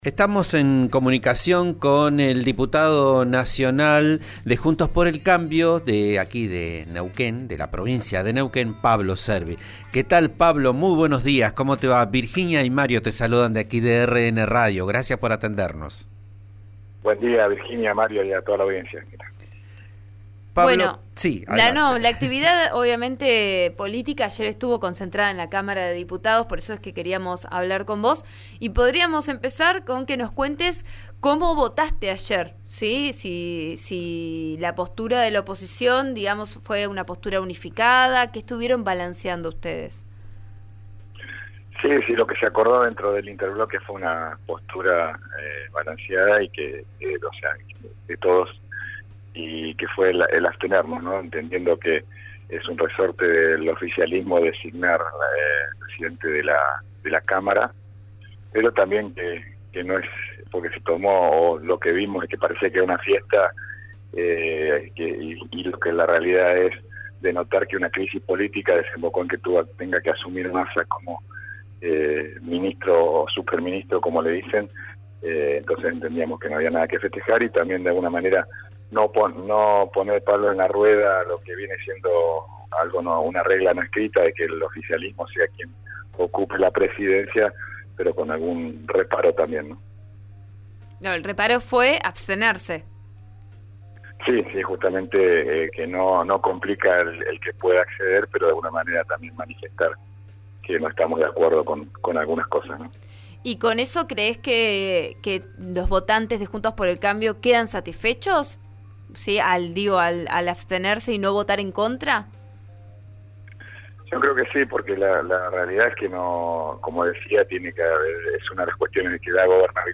Así lo explicó esta mañana en diálogo con Vos A Diario, por RN Radio, donde también anticipó su visión sobre el nuevo ministro de Economía de la Nación y las medidas que se esperan.